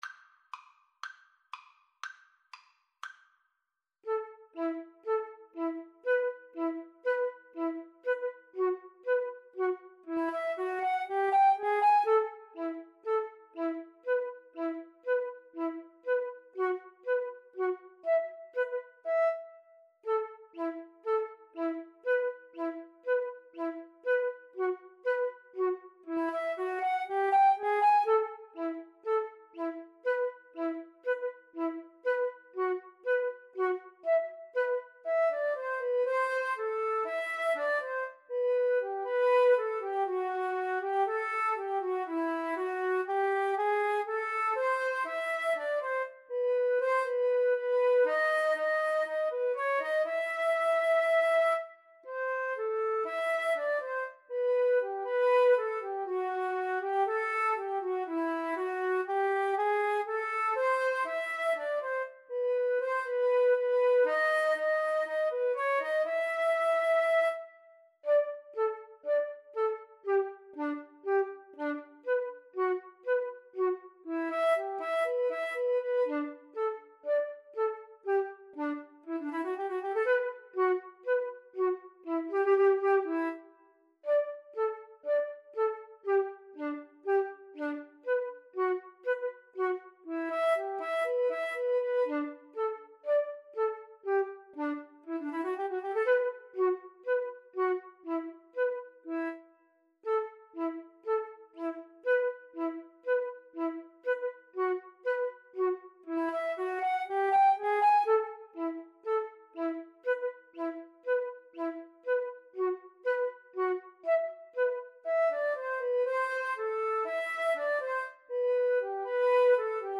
Fast Two in a Bar =c.120